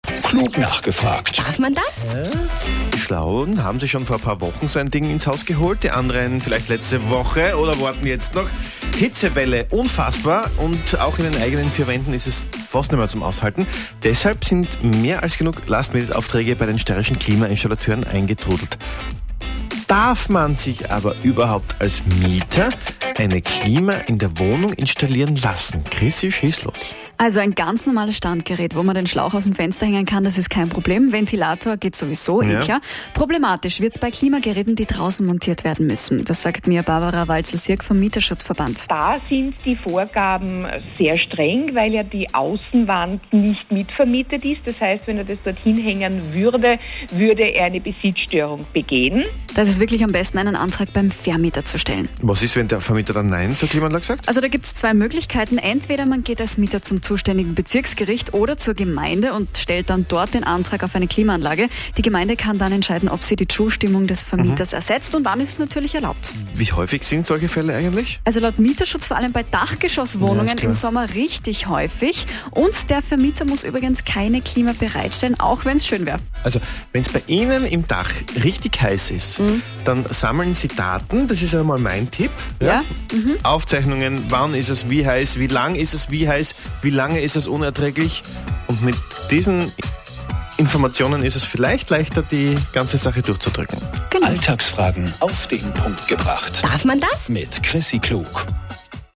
Darf man einfach so eine Klimaanlage installieren? Zu diesem Thema durften wir der Antenne Steiermark ein Interview geben.